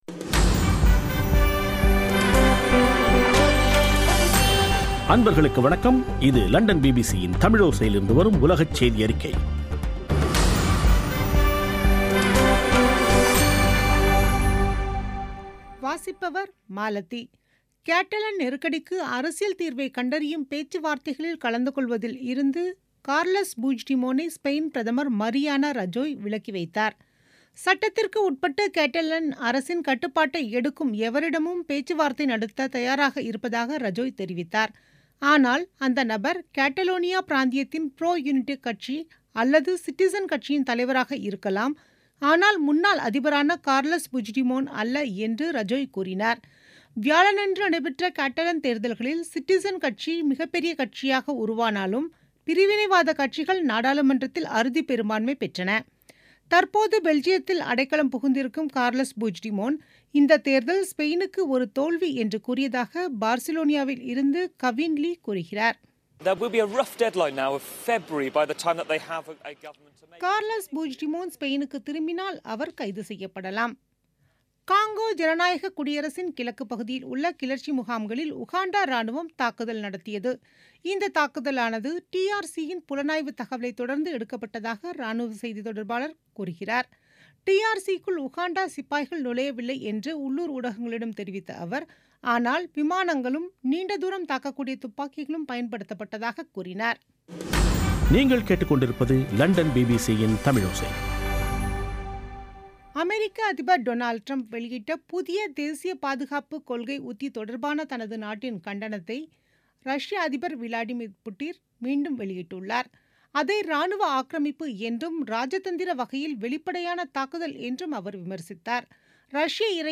பிபிசி தமிழோசை செய்தியறிக்கை (22/12/2017)